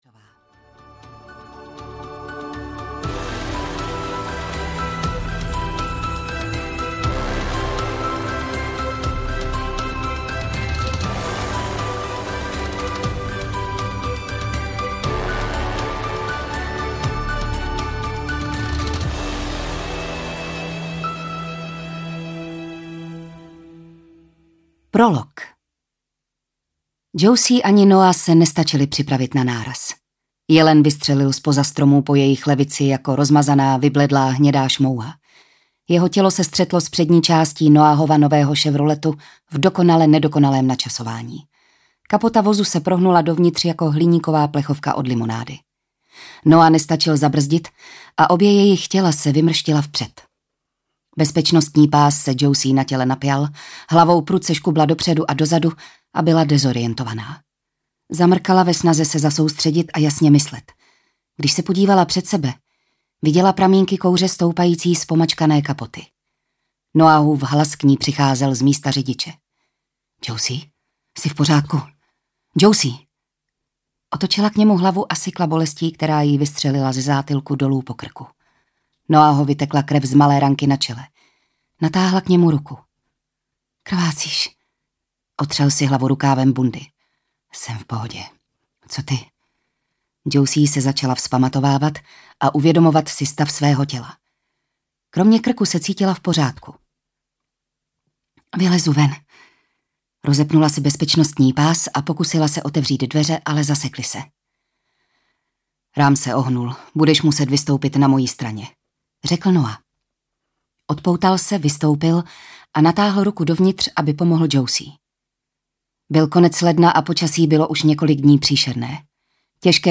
Temný úkryt audiokniha
Ukázka z knihy